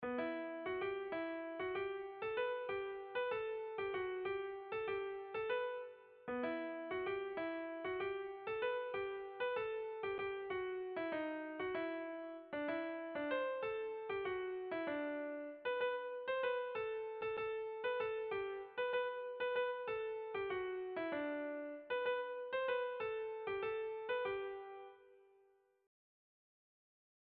Tailar honetan lagun ugari - Bertso melodies - BDB.
Zortziko handia (hg) / Lau puntuko handia (ip)
A1A2BD